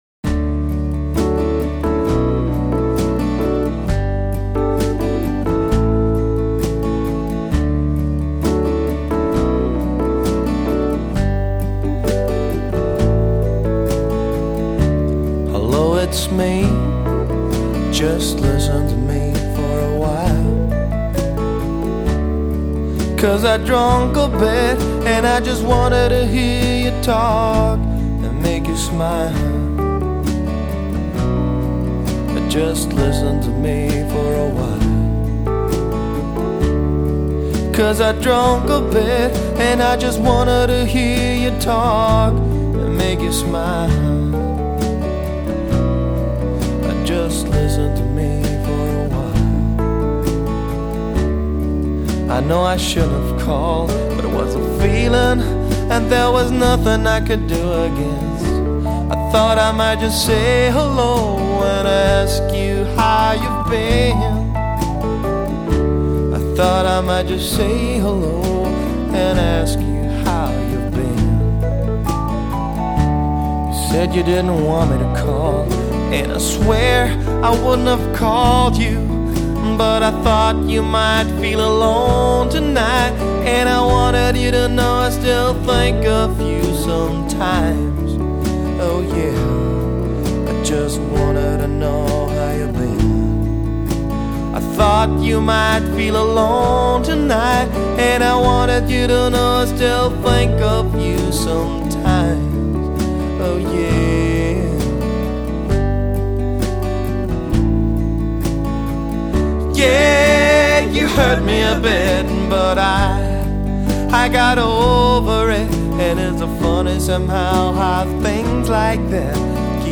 Alt. Version (2004)
Gesang, Gitarre
Background Gesang
Rhodes
Bass
Schlagzeug